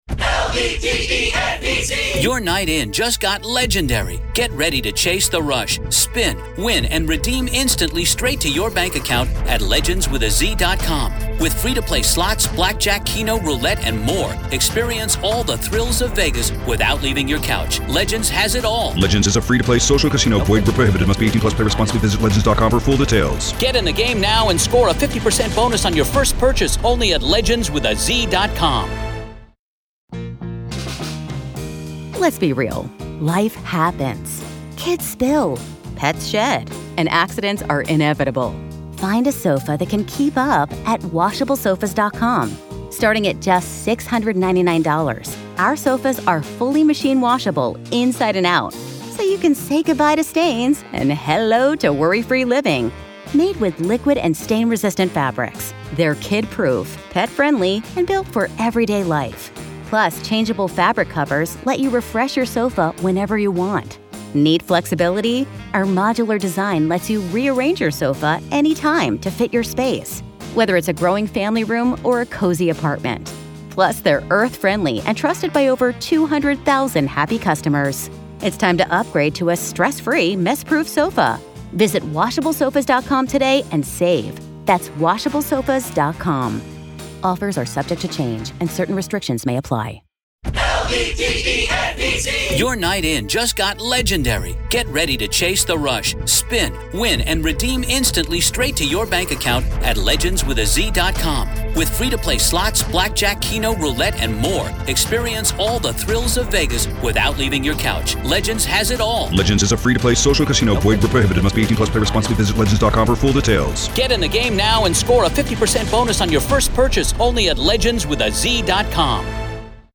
Court Audio-NEVADA v. Robert Telles DAY 4 Part 2